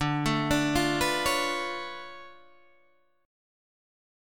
D Minor Major 13th